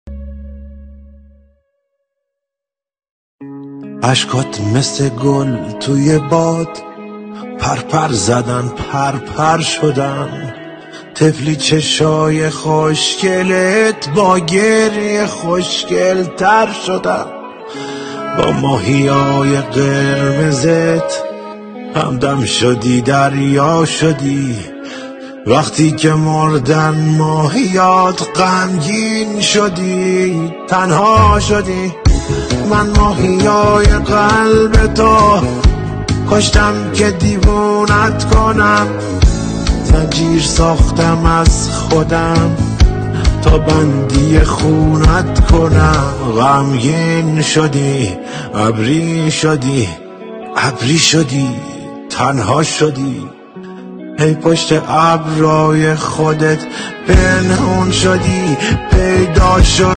دموی آهنگ را گوش کنید .